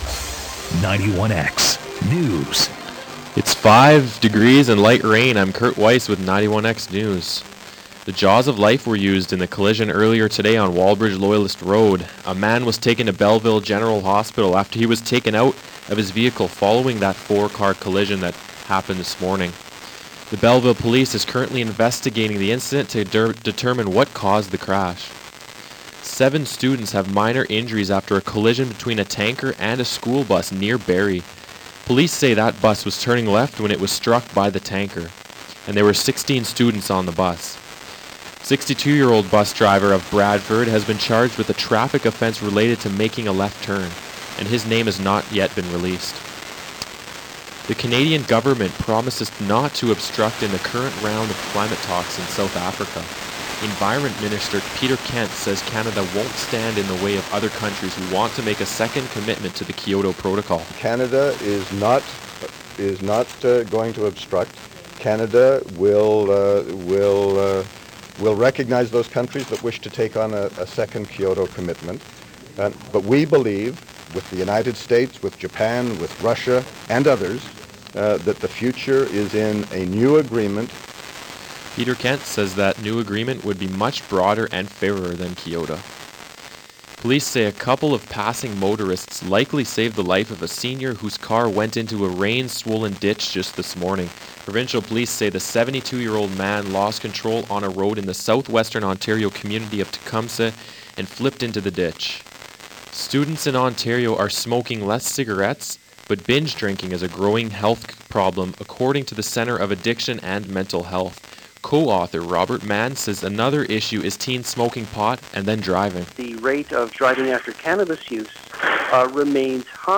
91x News
91x-news-nov.-29-2pm.mp3